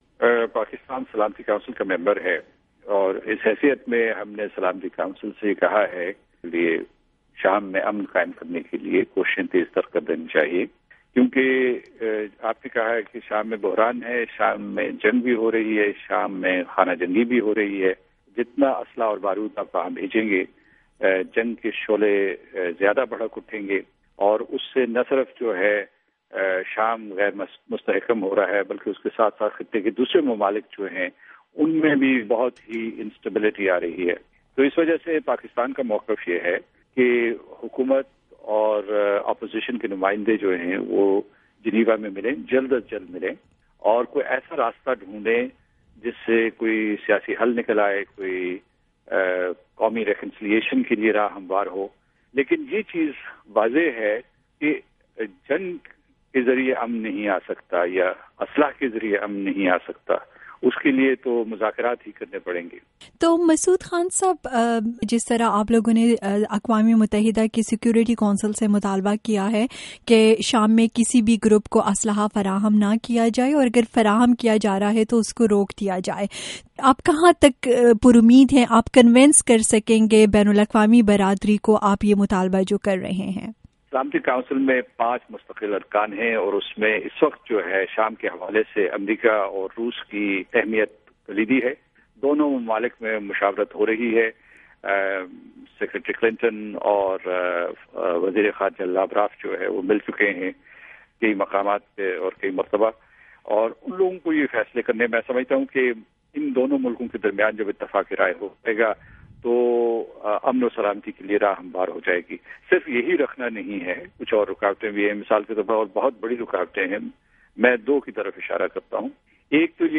اقوام متحدہ میں پاکستان کے مستقل مندوب، مسعود خان کا انٹرویو